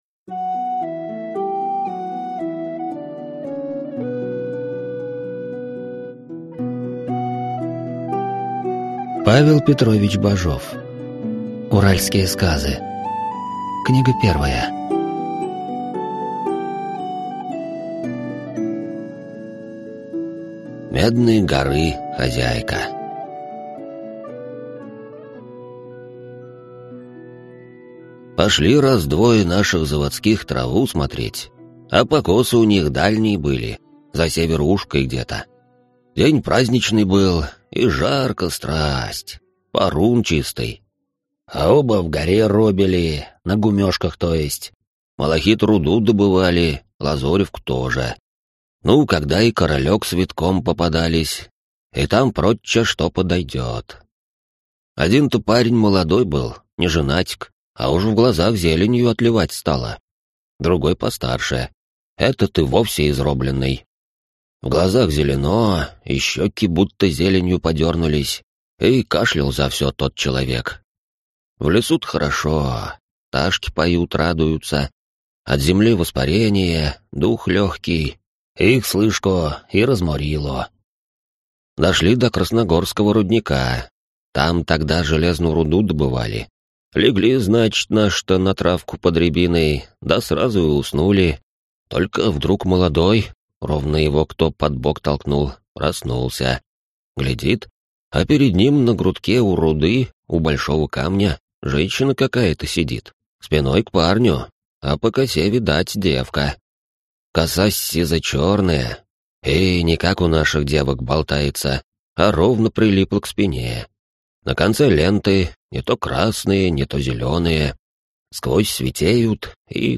Аудиокнига Уральские сказы | Библиотека аудиокниг